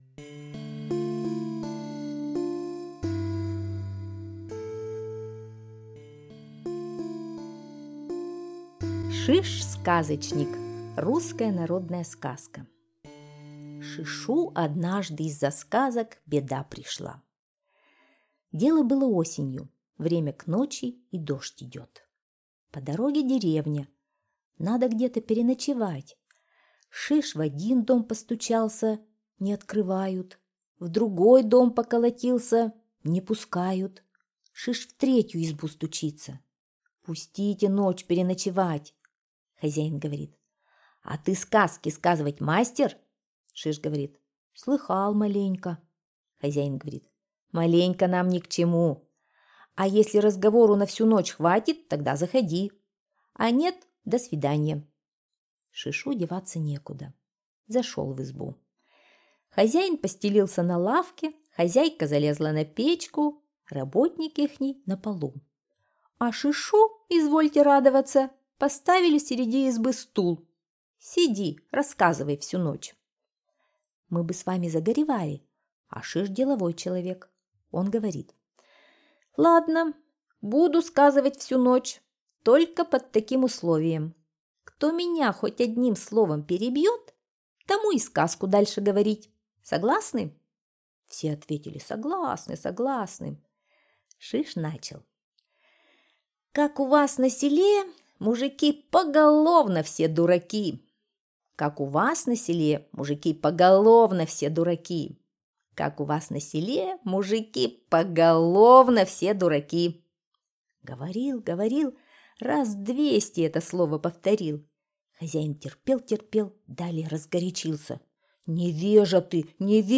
Шиш-сказочник. Сказки о Шише – русская народная аудиосказка